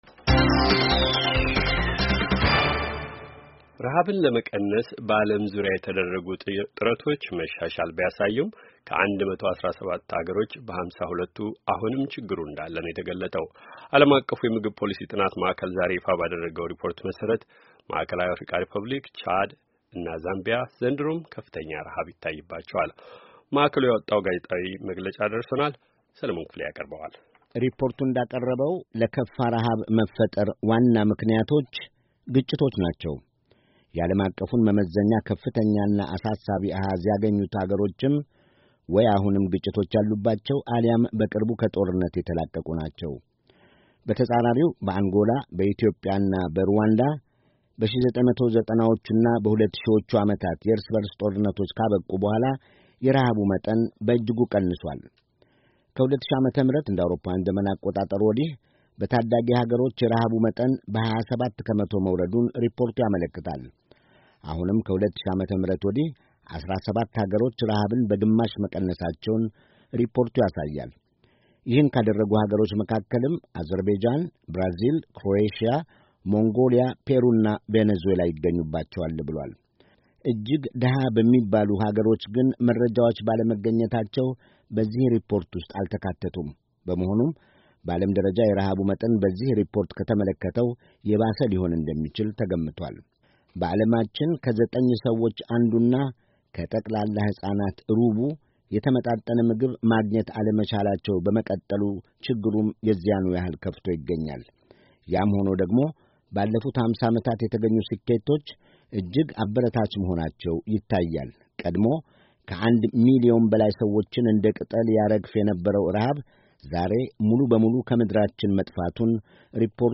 ዜና